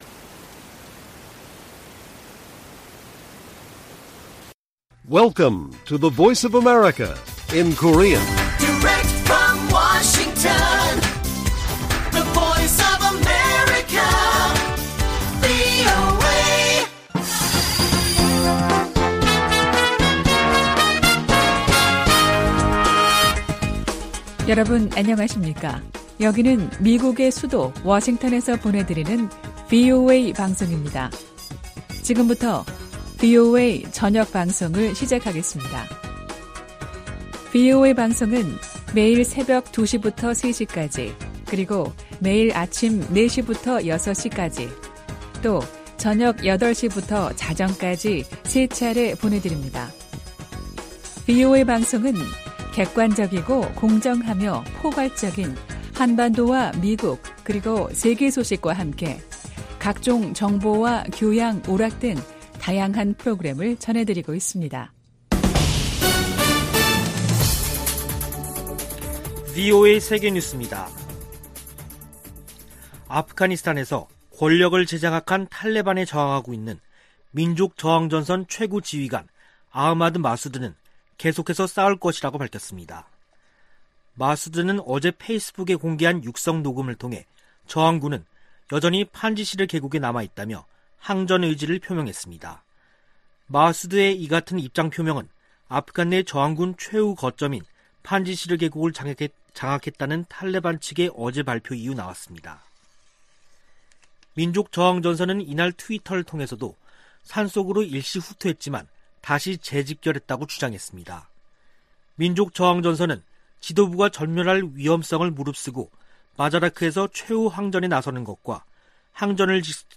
VOA 한국어 간판 뉴스 프로그램 '뉴스 투데이', 2021년 9월 7일 1부 방송입니다. 북한이 핵무기와 미사일 관련 국제 규칙을 노골적으로 무시하고 있다고 북대서양조약기구(NATOㆍ나토) 사무총장이 밝혔습니다. 오는 14일 개막하는 제 76차 유엔총회에서도 북한 핵 문제가 주요 안건으로 다뤄질 전망입니다. 아프가니스탄을 장악한 탈레반이 미군 무기를 북한에 판매하지 않을 것이라고 밝혔습니다.